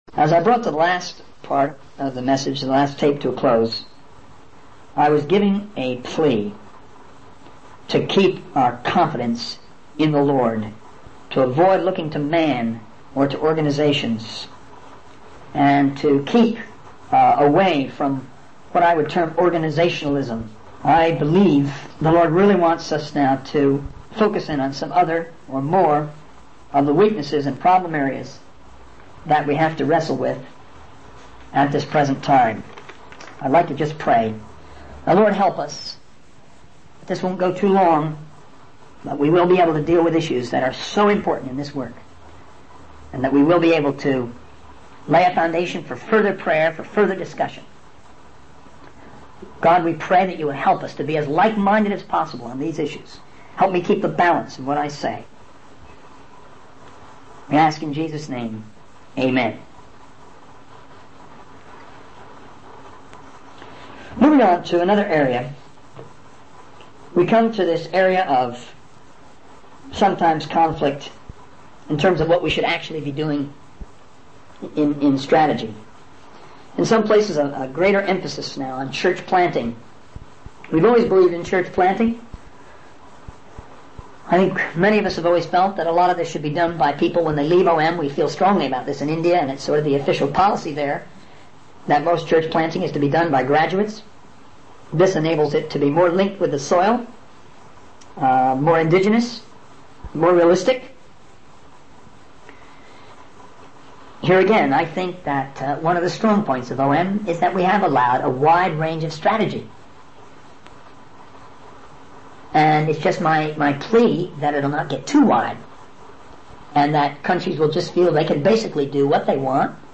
In this sermon, the speaker emphasizes the importance of unity and moving in the same direction as a movement. They highlight the need for leaders to lead by example and not expect others to do things they are not doing themselves.